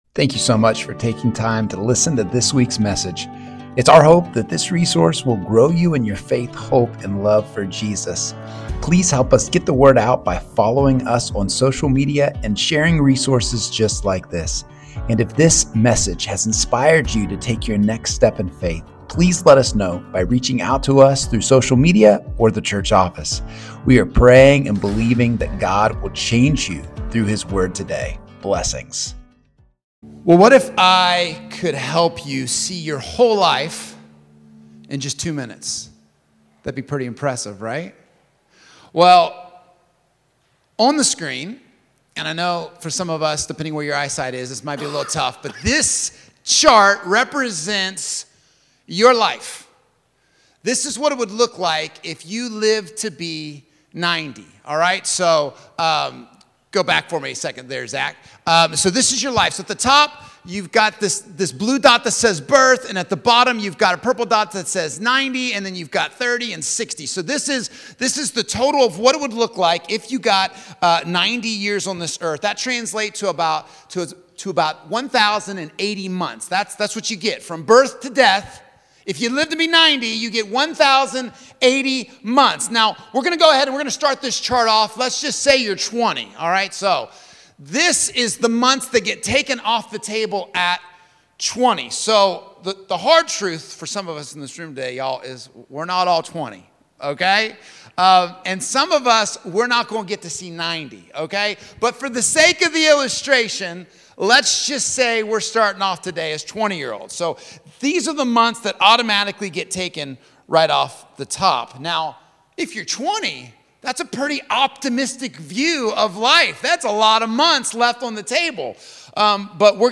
Sermons | First Baptist Church of St Marys